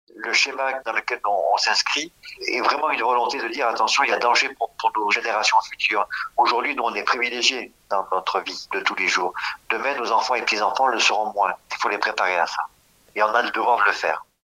Une interview réalisée à l’occasion de leur présence sur l’événement Ta fête, organisé par Habilis le 21 mai à Barbentane, dont Bleu Tomate est partenaire.